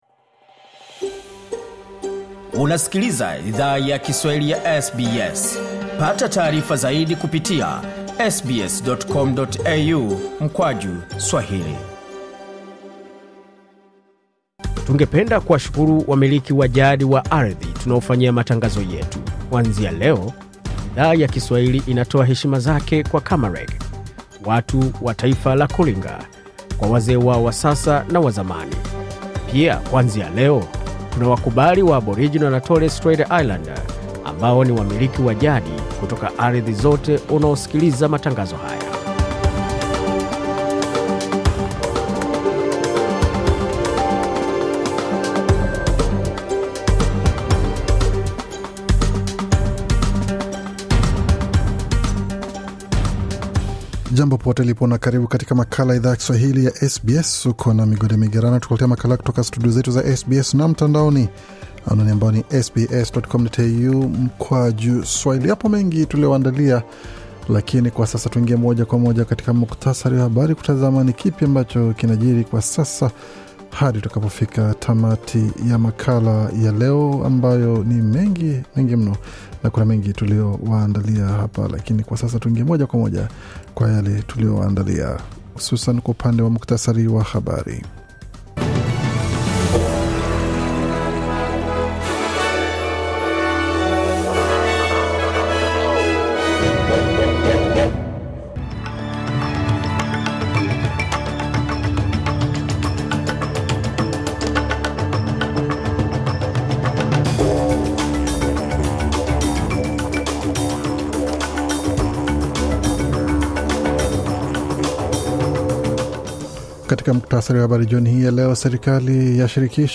Taarifa ya Habari 2 Aprili 2023